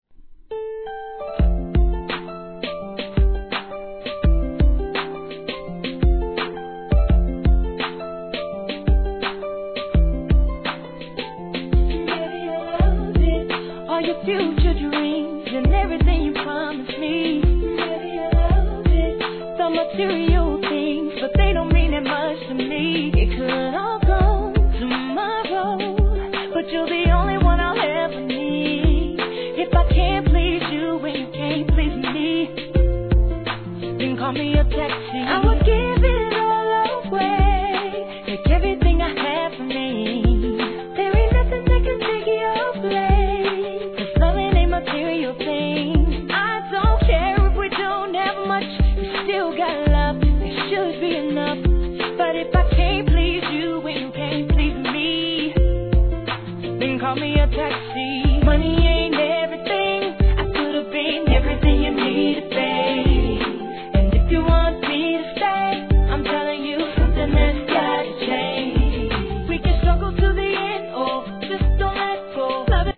1. HIP HOP/R&B
流行に関係なく、極上美メロだけを収録のラグジュアリーR&Bコンピ!!